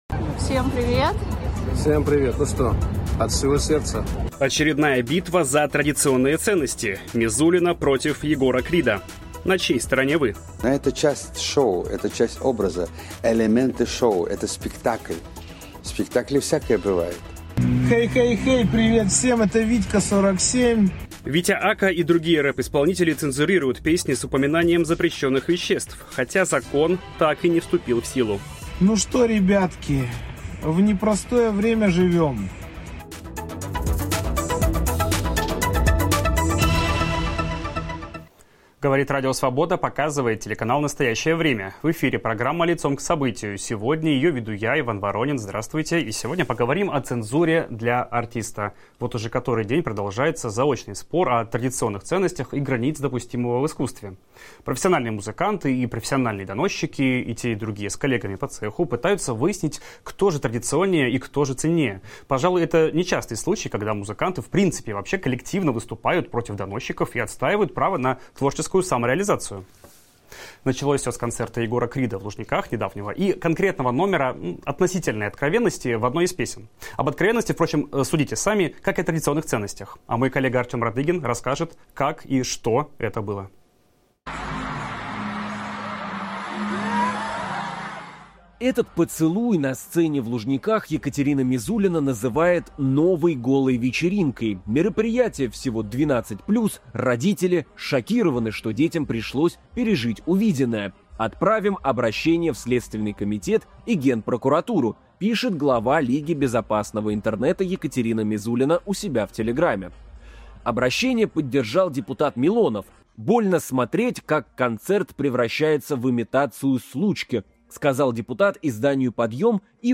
О цензуре в российском шоу-бизнесе говорим в программе "Лицом к событию" с музыкальным журналистом